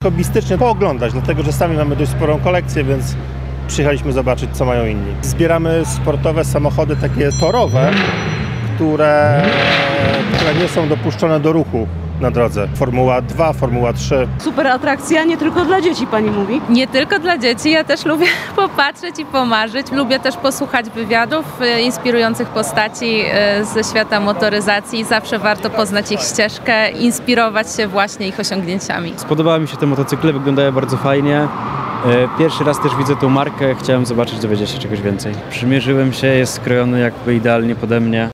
– mówi jeden z uczestników wydarzenia.